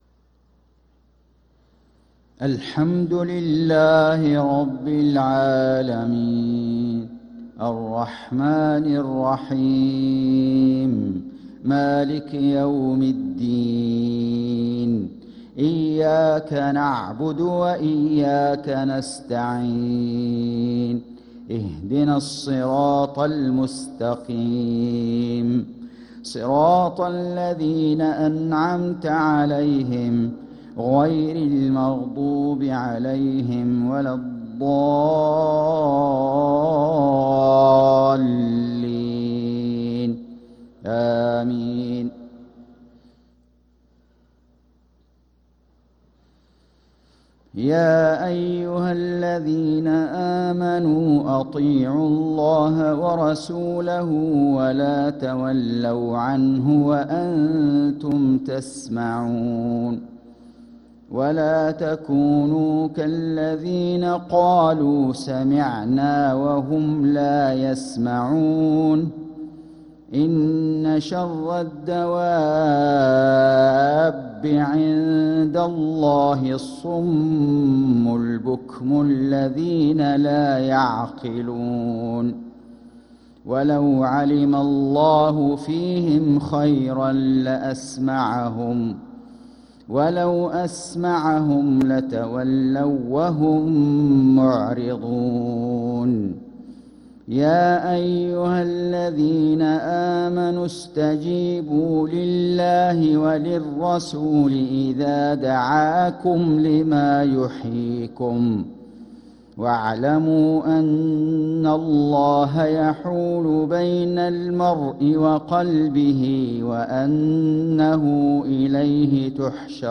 صلاة العشاء للقارئ فيصل غزاوي 13 صفر 1446 هـ
تِلَاوَات الْحَرَمَيْن .